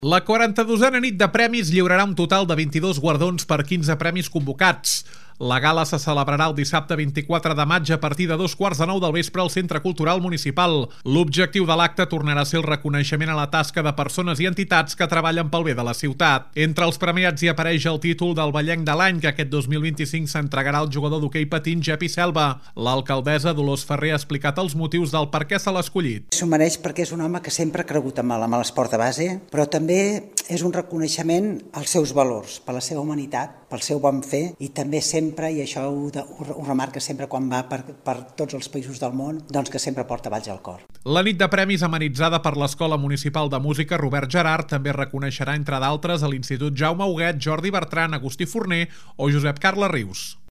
L’alcaldessa, Dolors Farré, ha explicat els motius del perquè se’l ha escollit.